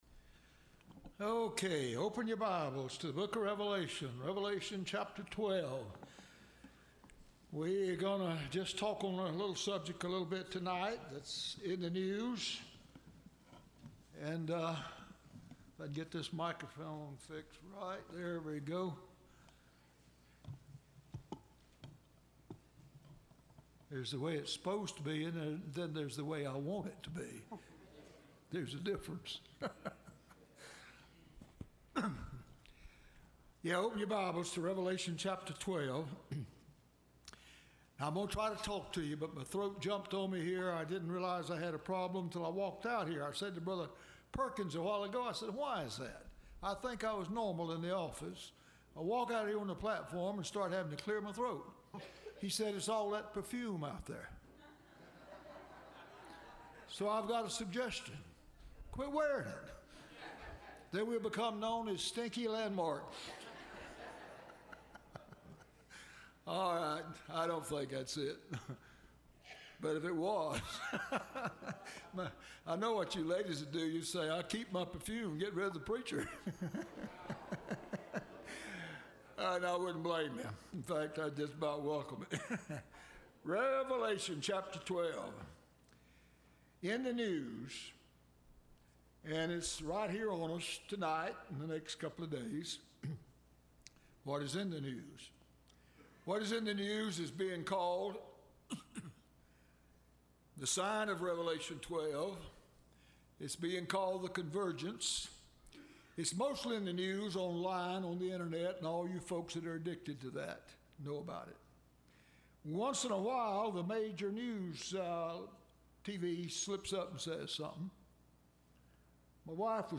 Listen to Message
Service Type: Wednesday